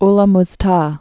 (lə məz-tä, -täg)